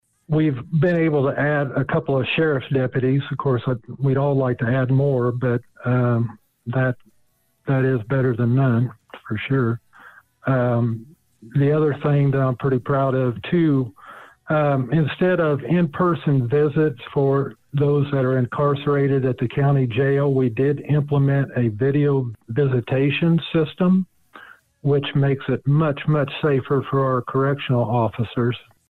Two members who will be leaving the Vermilion County Board were thanked during Tuesday night’s meeting for their service.